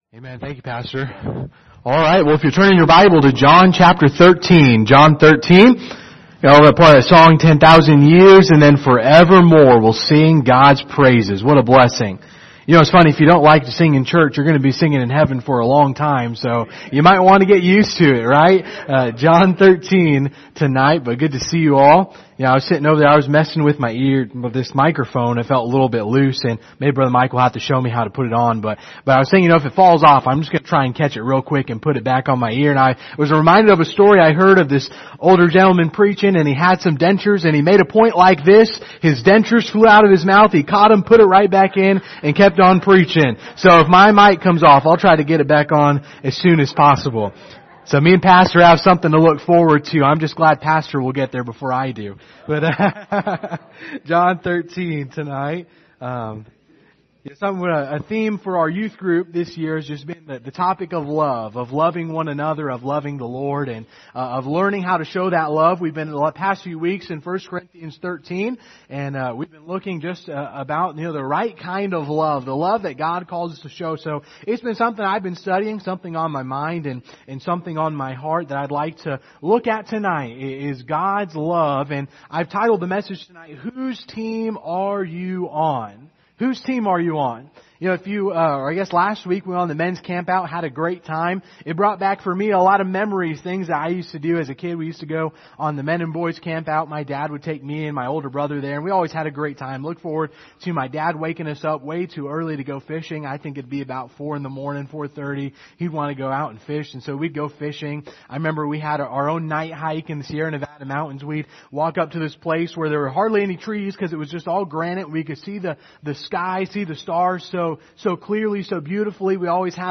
General Passage: John 13:34-35 Service Type: Sunday Evening View the video on Facebook « Pattern for Prayer Using the Tabernacle